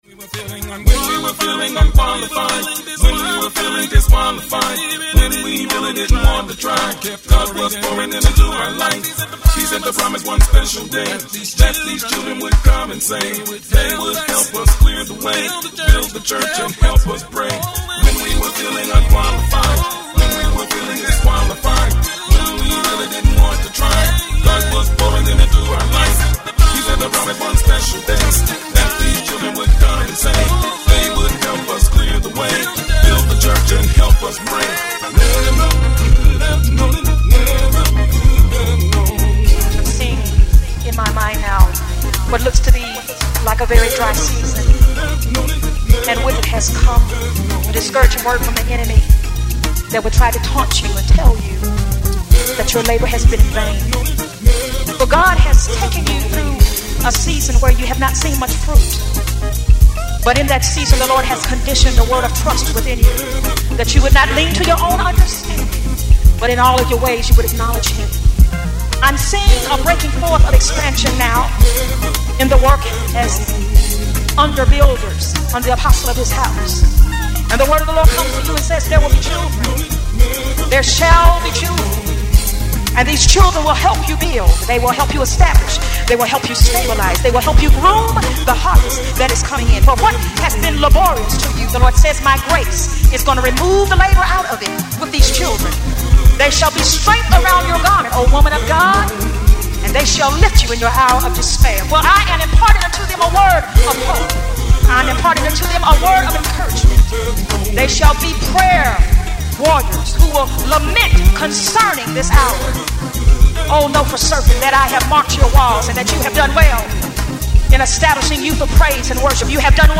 uniquely, simplistic style